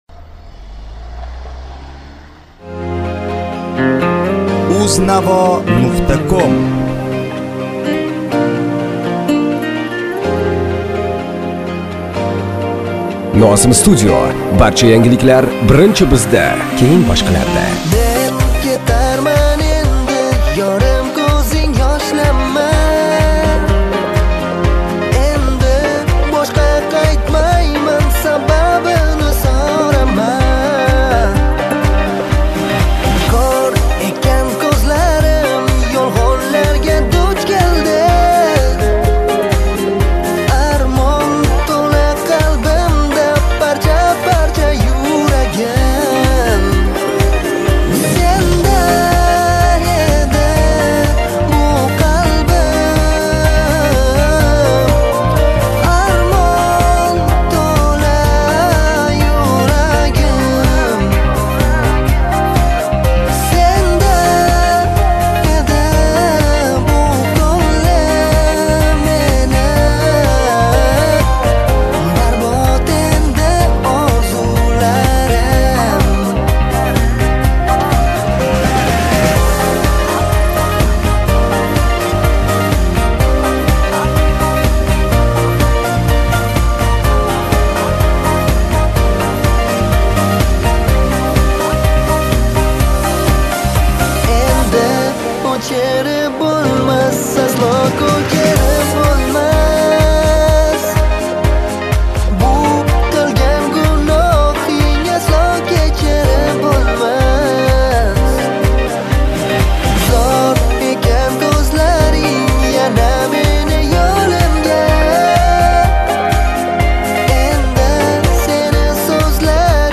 minus скачать мр3 2022